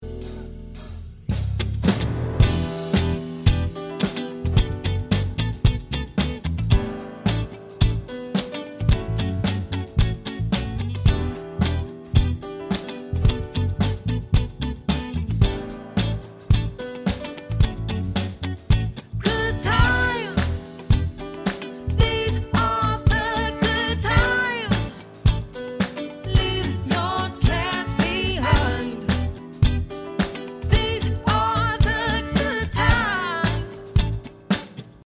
party/cover band
fem musiker